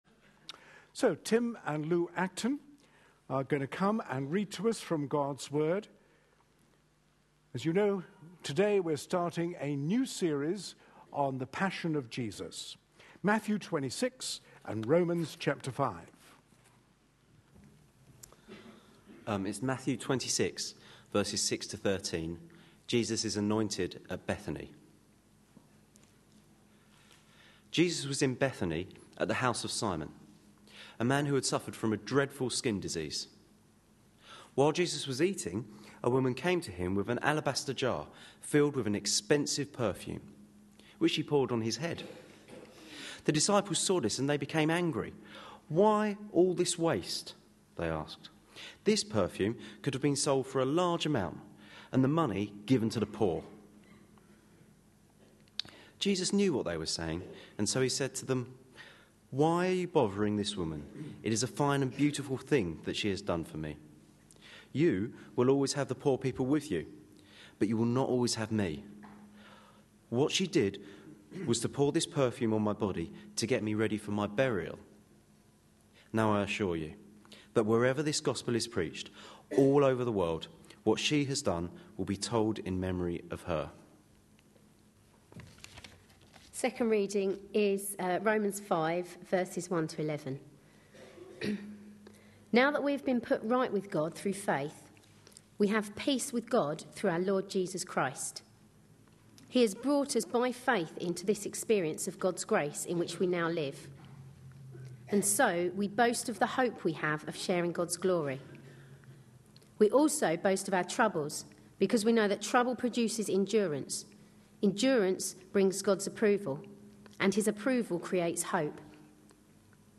A sermon preached on 10th February, 2013, as part of our Passion Profiles and Places -- Lent 2013. series.